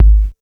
808s
Bass Kick 3k.wav